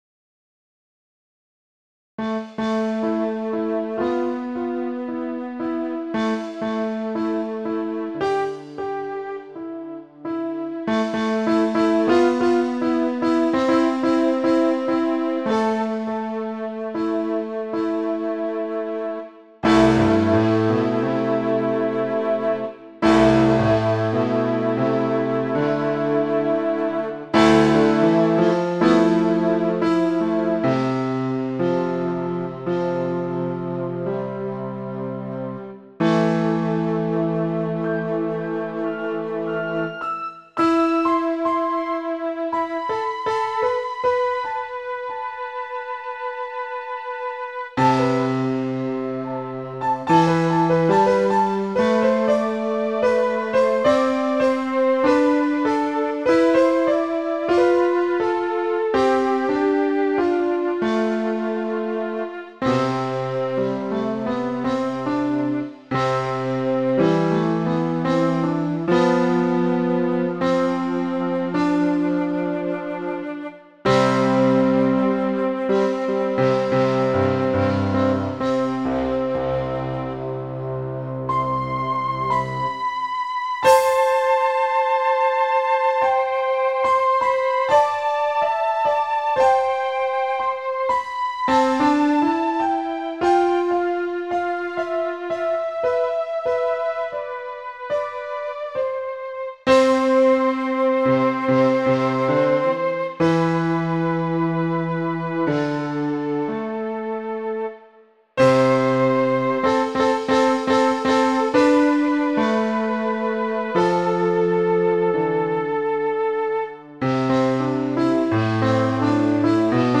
Posted in Classical Comments Off on